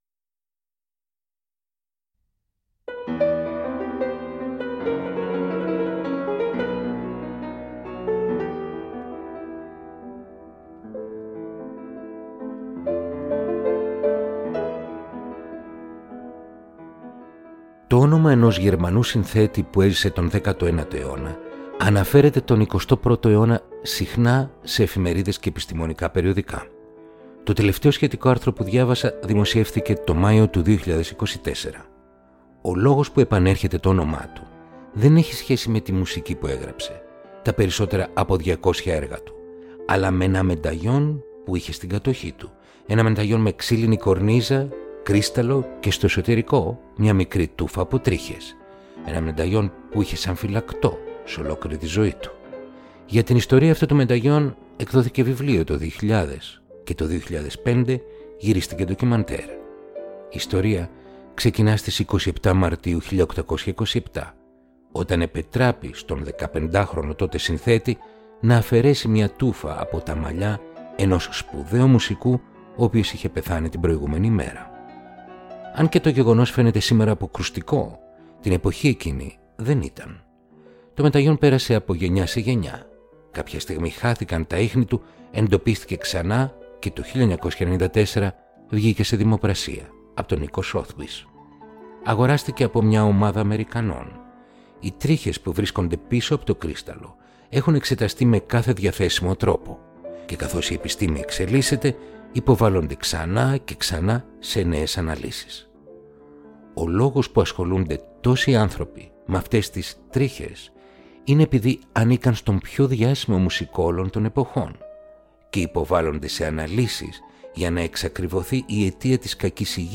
Ρομαντικά κοντσέρτα για πιάνο – Επεισόδιο 35ο